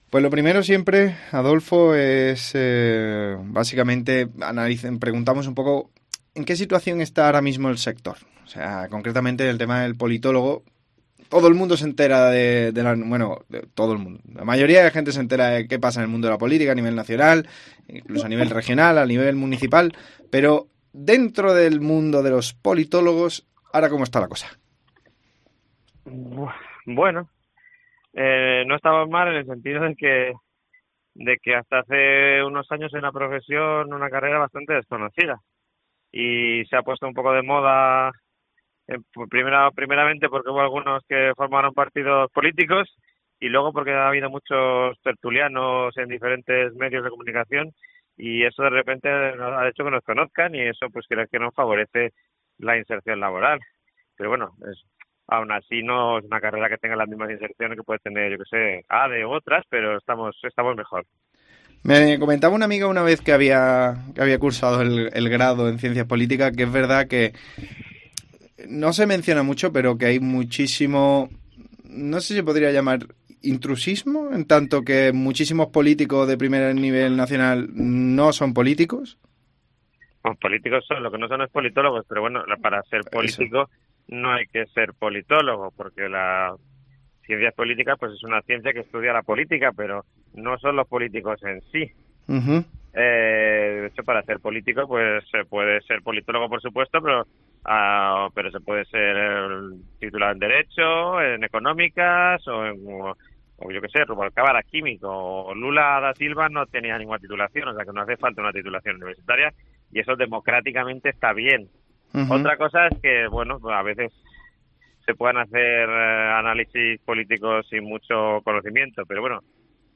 CUÁNTO COBRA UN POLITÓLOGO El margen de salarios es tan grandes que nuestro entrevistado no puede especificar entre qué horquilla se mueven.